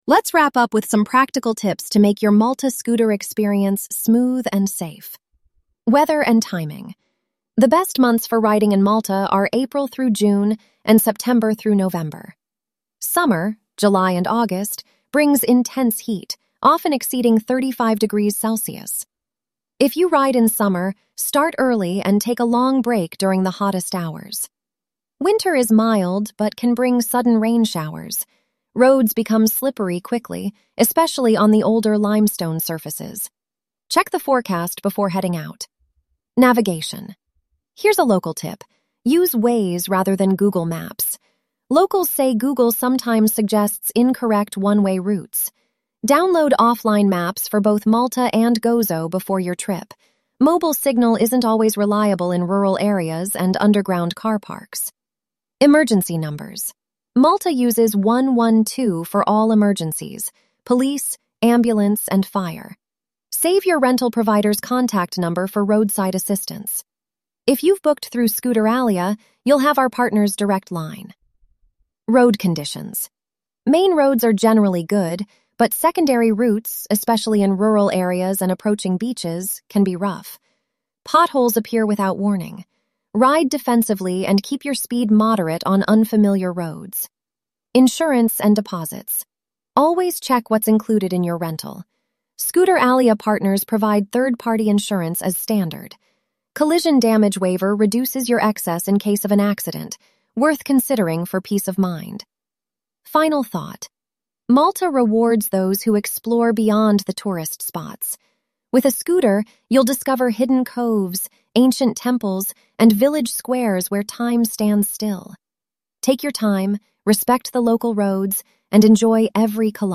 🎧 Malta Scooter Riding Audio Guide